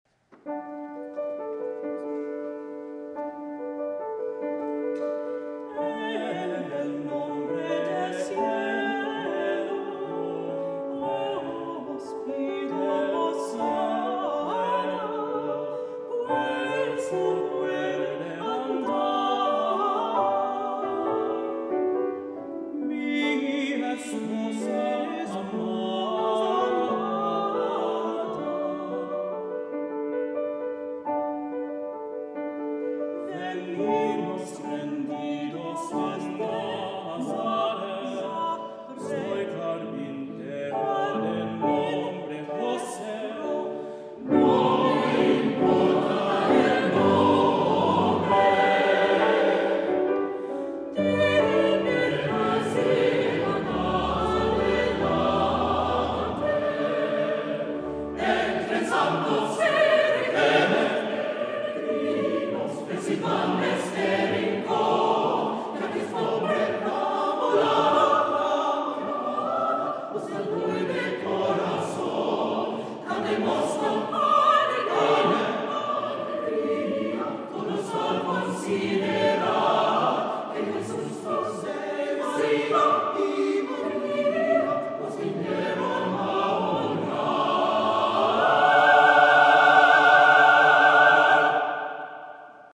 Soli/SATB + Piano 2’10”